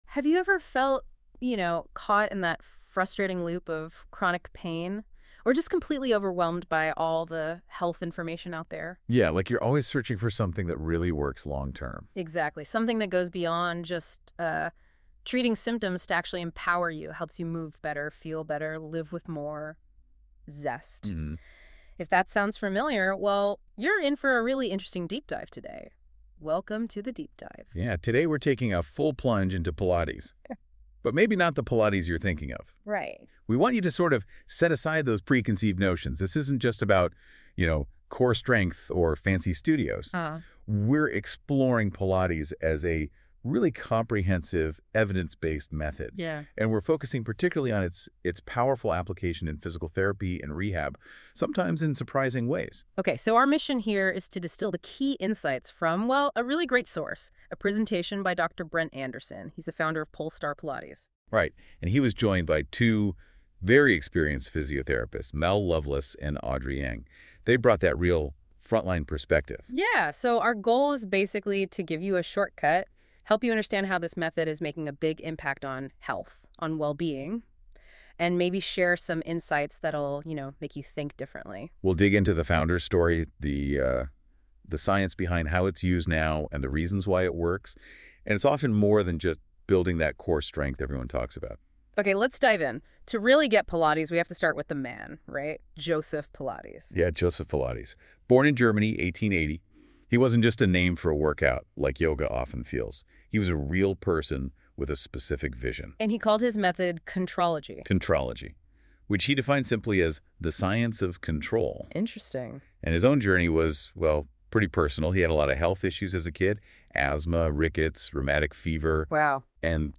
Check out this 1-hour webinar which we ran exclusively for New Zealand-based physiotherapists.